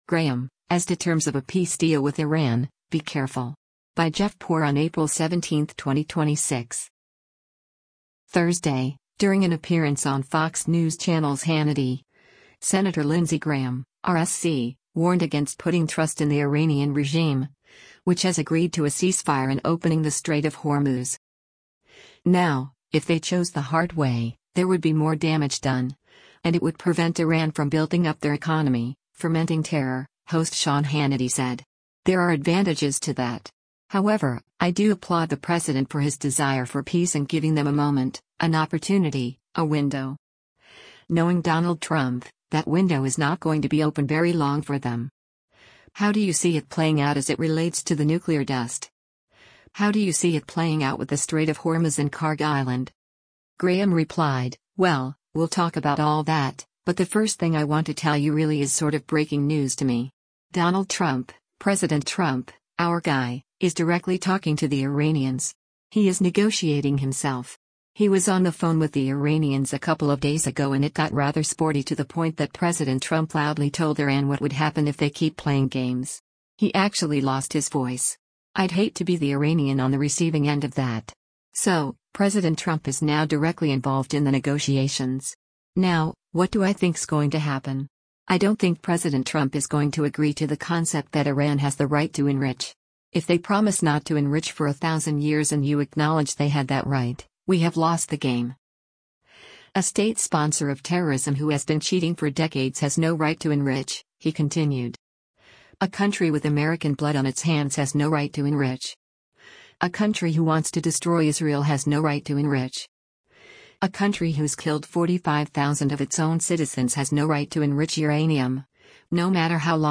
Thursday, during an appearance on Fox News Channel’s “Hannity,” Sen. Lindsey Graham (R-SC) warned against putting trust in the Iranian regime, which has agreed to a ceasefire and opening the Strait of Hormuz.